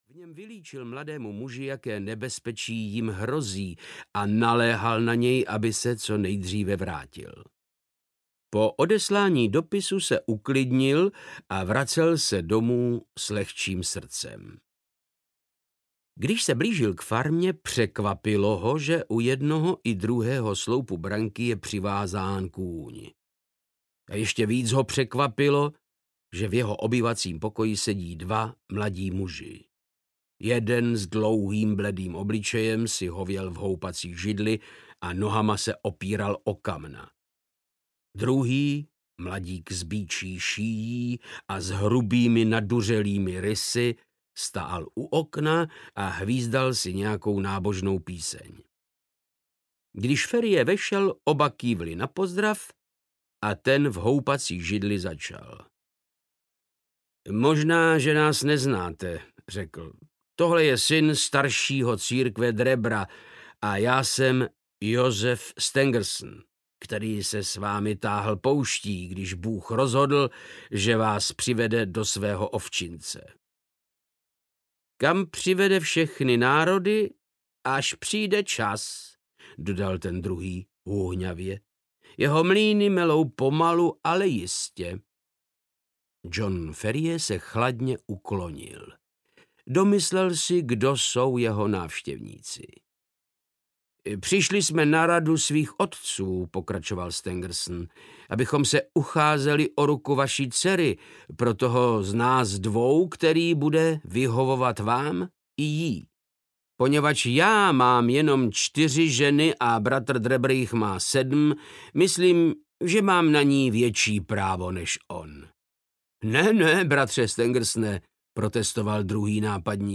Studie v šarlatové – část 5. audiokniha
Ukázka z knihy
• InterpretVáclav Knop